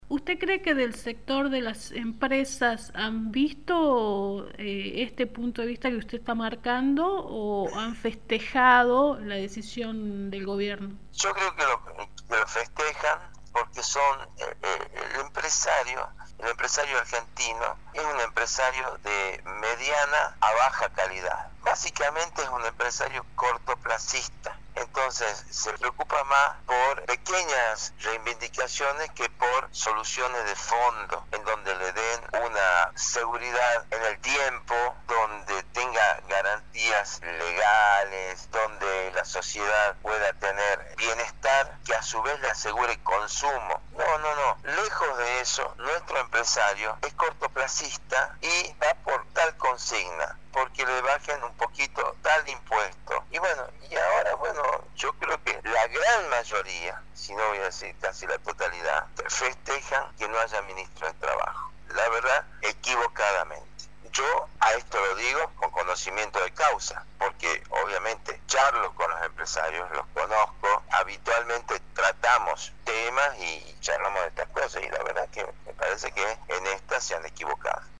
LA ENTREVISTA: